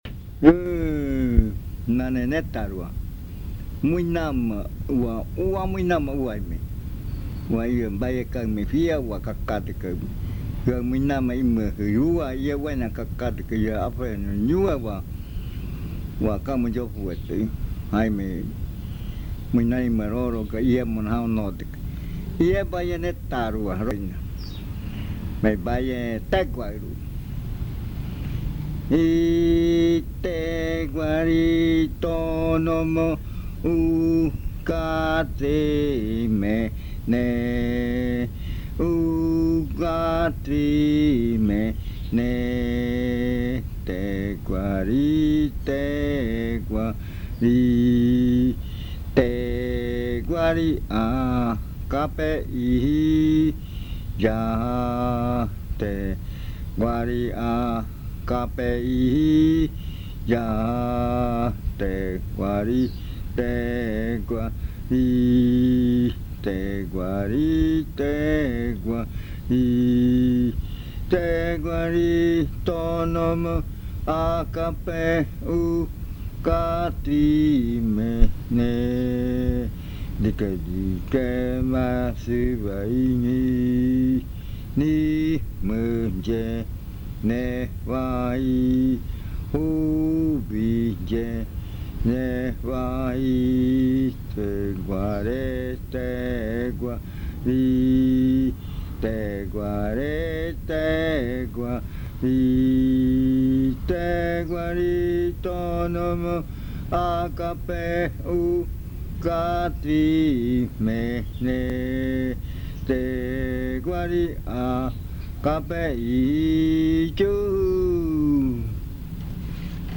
Leticia, Amazonas
Cantado en muinane.
ritual de frutas
Cantos de yuakɨ